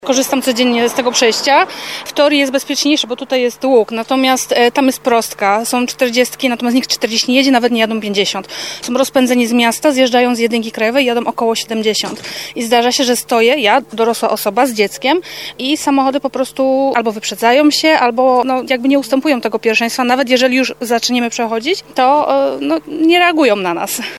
Oddajmy głos mieszkance Ligoty, która codziennie, wraz z dziećmi, korzysta z pasów na prostym odcinku ul. Czechowickiej.